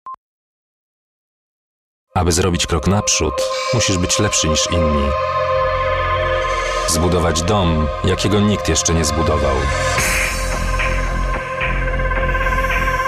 Sprecher polnisch.
Kein Dialekt
Sprechprobe: Industrie (Muttersprache):